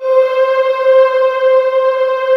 Index of /90_sSampleCDs/USB Soundscan vol.28 - Choir Acoustic & Synth [AKAI] 1CD/Partition D/18-HOLD VOXS